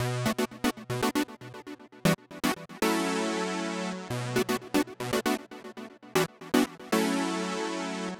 11 Chord Synth PT1.wav